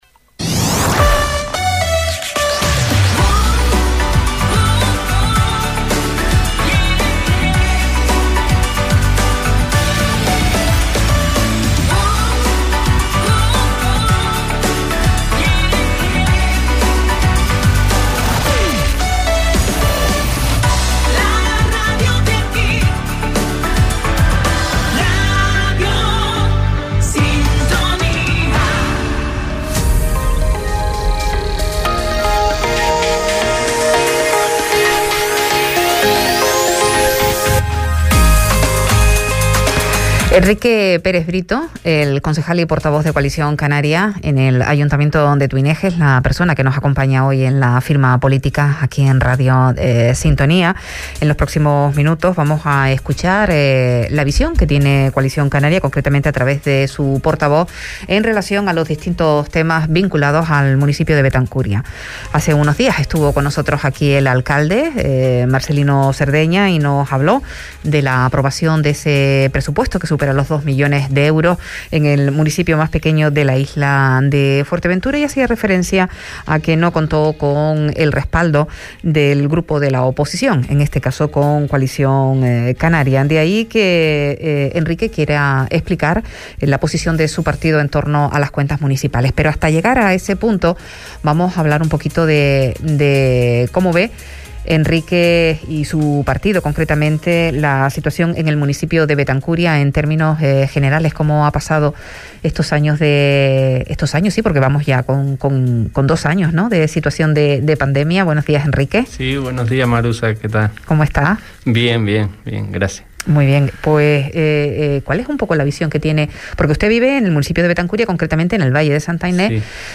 Entrevista a Enrique Pérez, concejal de Coalición Canaria en Betancuria – 15.12.21 Deja un comentario
Entrevistas